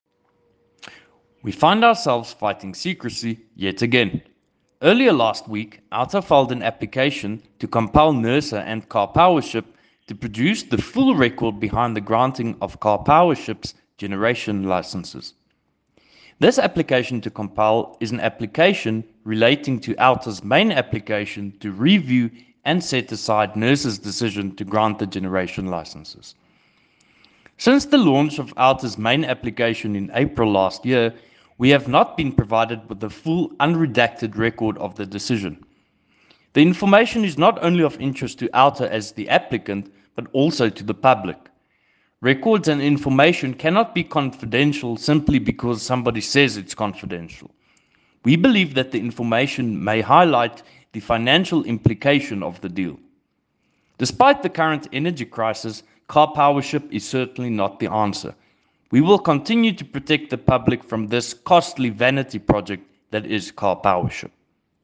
A soundclip with comment